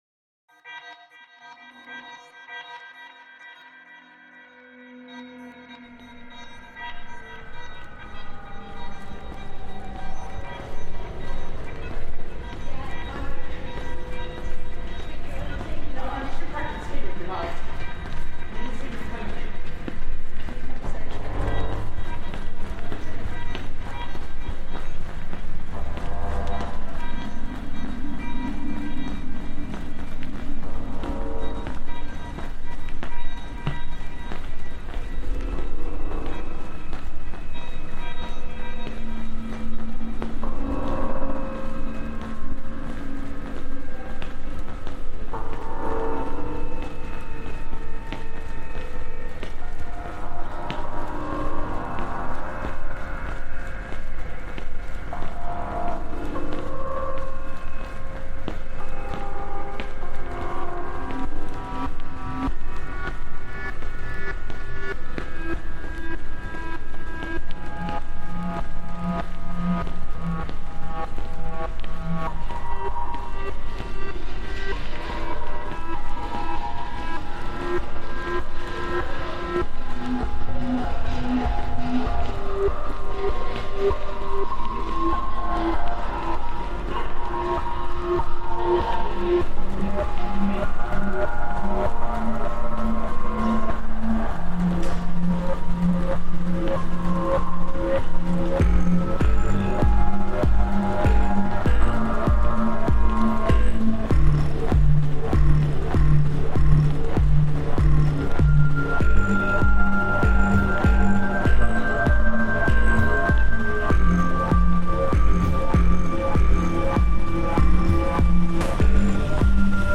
Corridors at the Barbican Centre, London reimagined